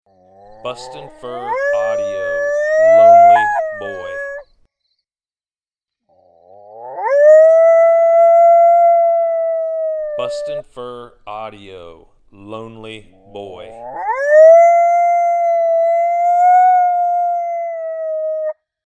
Apollo sad and lonely howling, this howl is so mellow, it will call in all age structures of coyotes. Excellent stand starter howl.